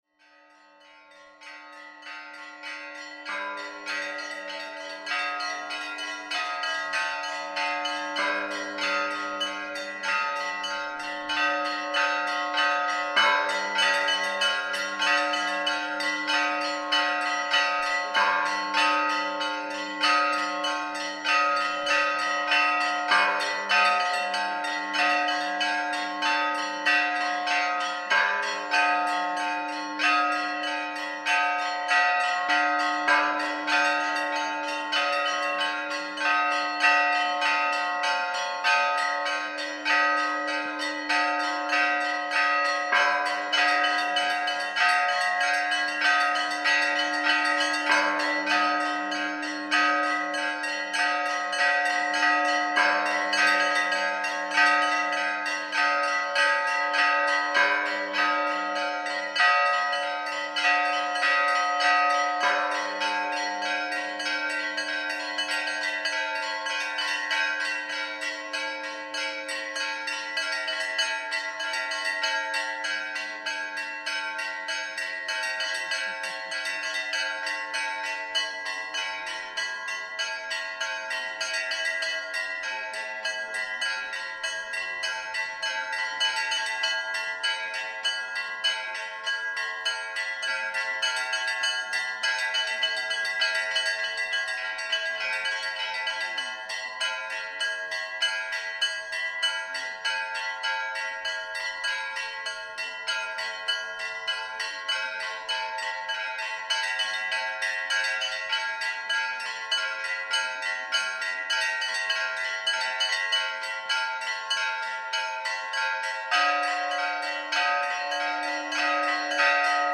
Traditional bell ringing
Svetitskhoveli Cathedral is an Orthodox Christian cathedral located in Georgia's oldest city and former capital, Mtskheta. As I passed through the main entrance and walked through the internal grounds, bell ringers began ringing the bells from high up in one of the towers.